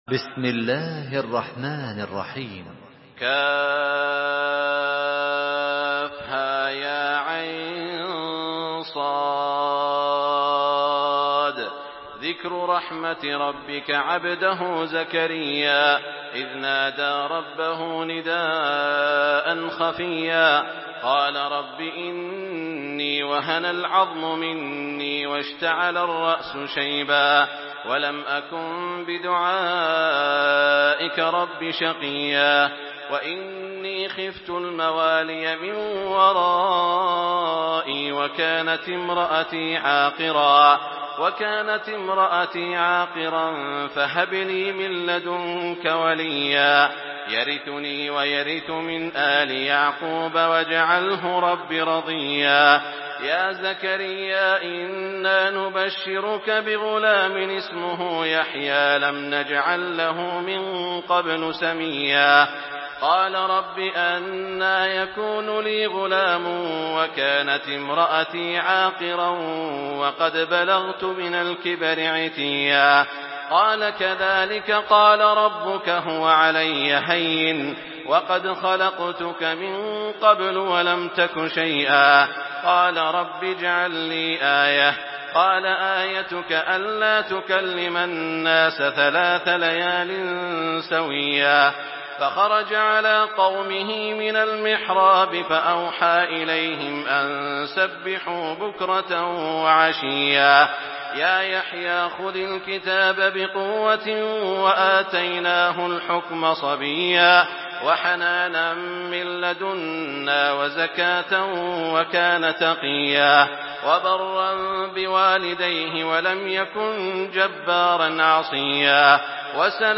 تحميل سورة مريم بصوت تراويح الحرم المكي 1427